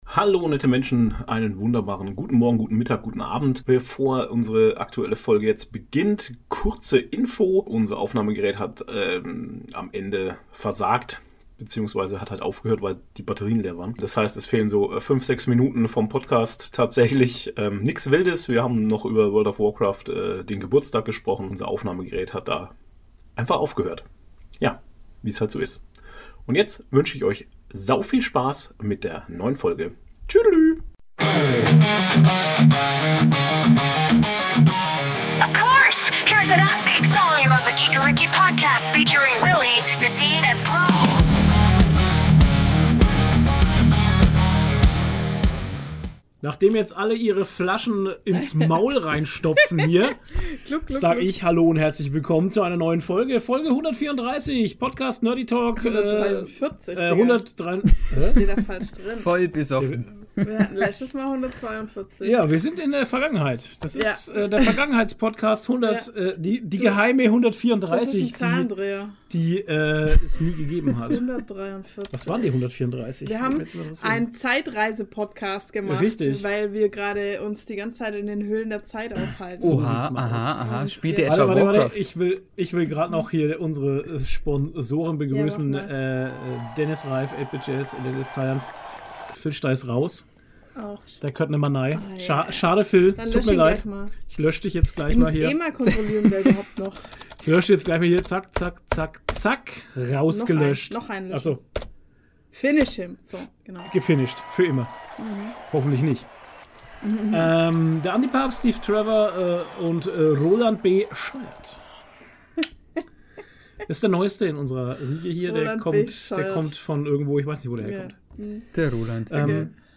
Nerdy Talk #143: Der Pizza Podcast Live aus der Halloween Hauptstadt ~ Der Nerdy Talk von Geekeriki Podcast